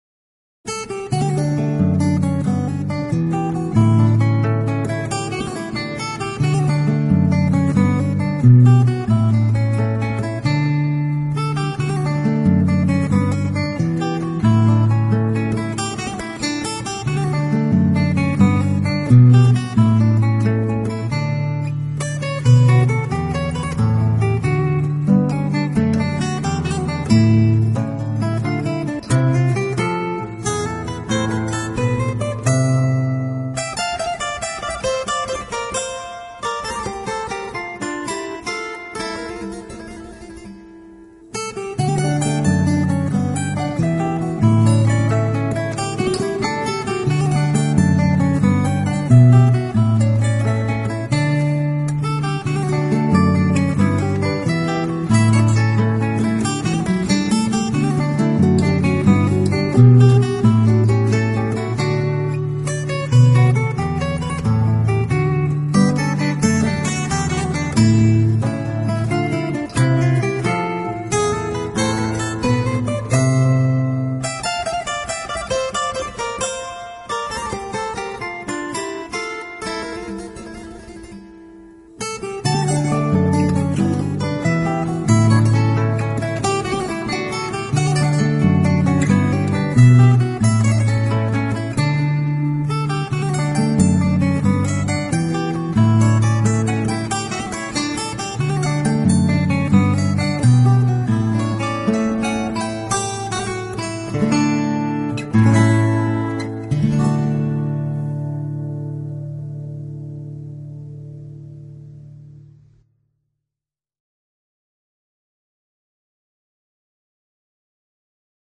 音乐风格：New Age/World/Folk/Rock